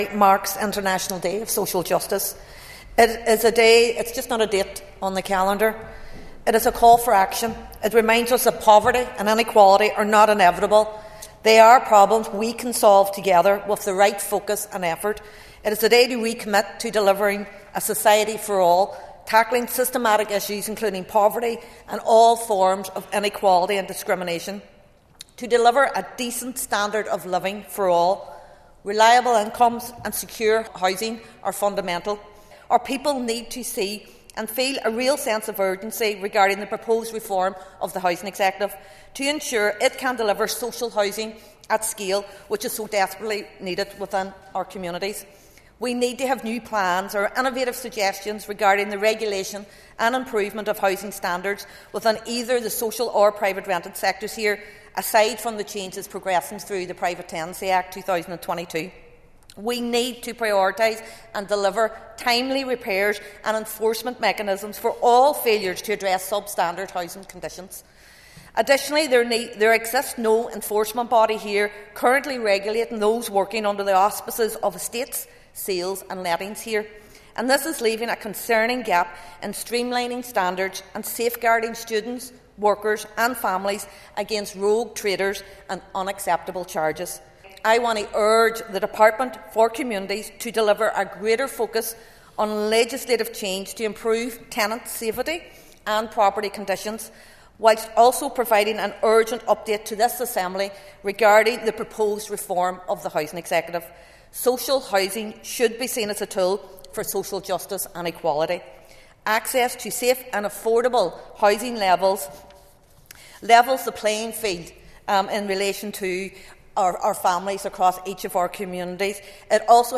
Foyle MLA Ciara Ferguson told the Assembly the Housing Executive is in need of reform, and today’s theme should give all agencies involved the impetus to act.